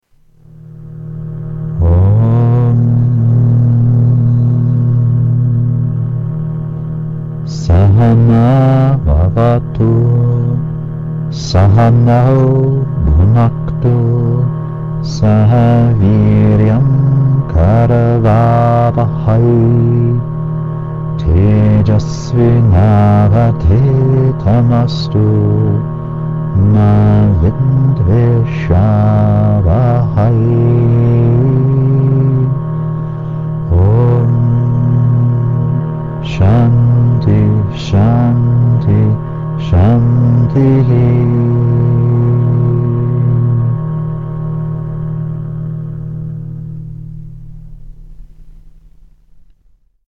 Yoga Chants